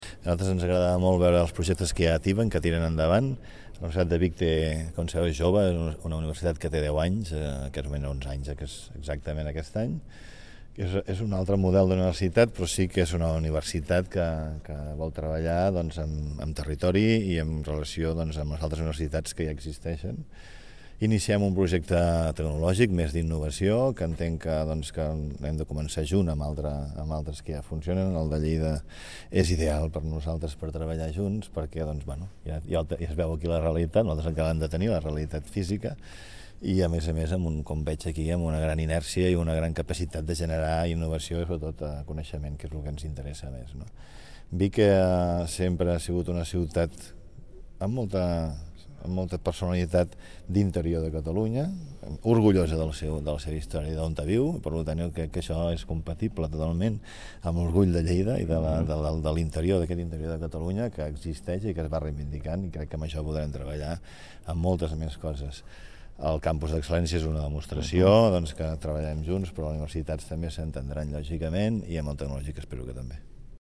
(1.0 MB) Declaracions de l'alcalde de Vic.
declaracions-de-lalcalde-de-vic